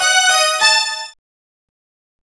Synth Lick 50-11.wav